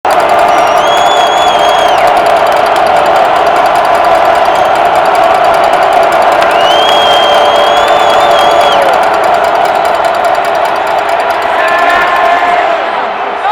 5. 1. 관중 소음